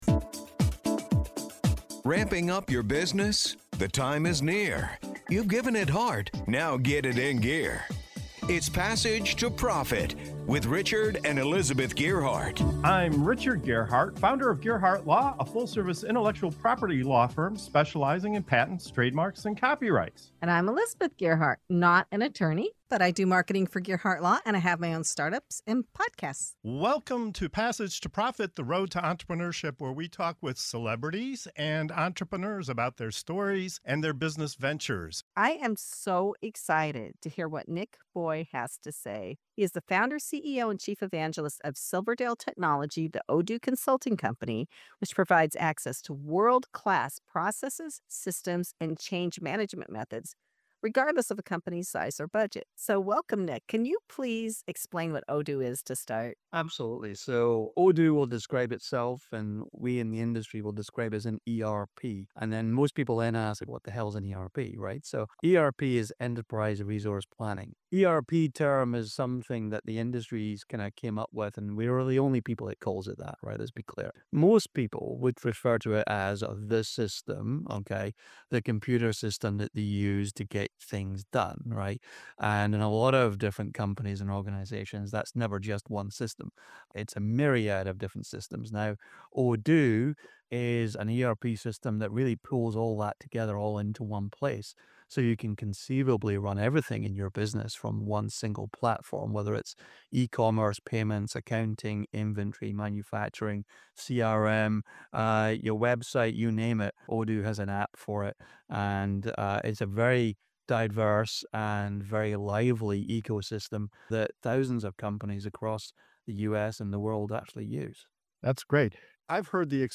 Passage to Profit Show interview